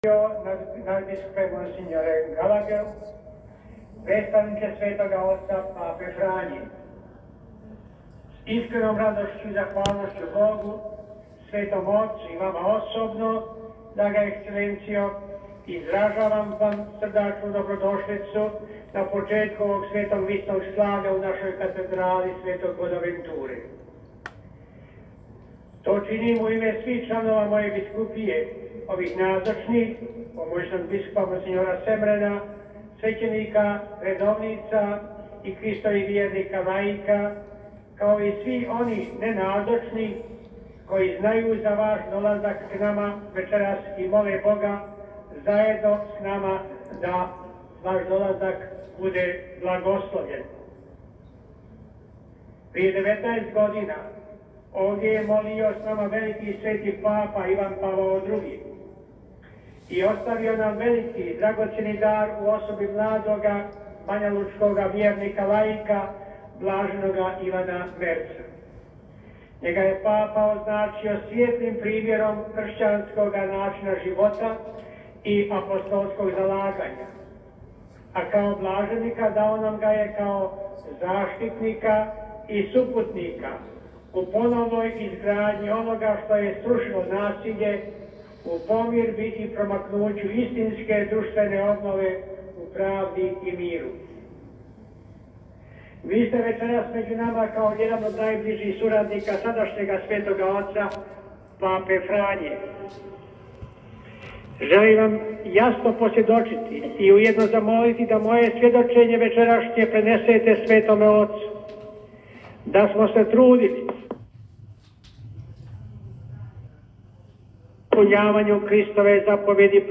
U večernjim satima u petak, 18. ožujka 2022. tajnik za odnose s državama pri Državnom tajništvu Svete Stolice nadbiskup Paul Richard Gallagher pohodio je sjedište Banjolučke biskupije te u katedrali sv. Bonaventure predvodio svečano Euharistijsko slavlje. Riječi dobrodošlice u ime svih uputio mu je biskup banjolučki mons. Franjo Komarica.